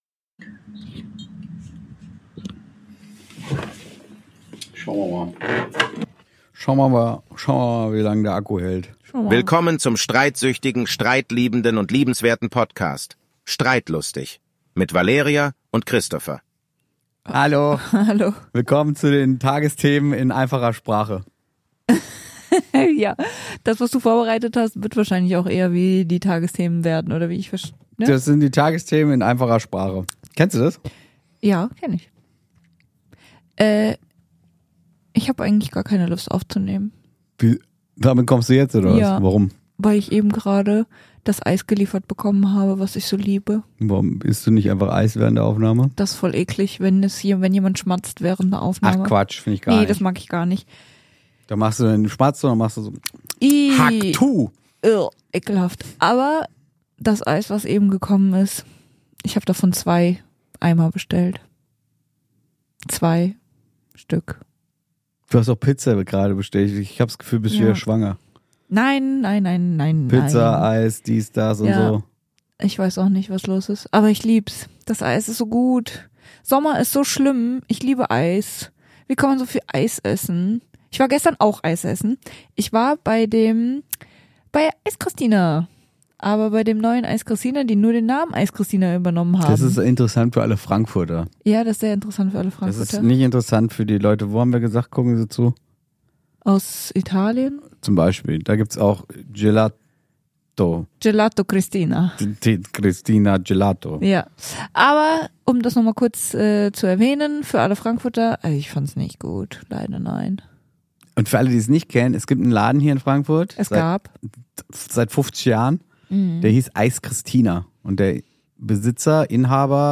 Hier wird gelacht, geredet und ja, manchmal auch gestritten, denn was wäre das Leben ohne eine gesunde Portion Leidenschaft?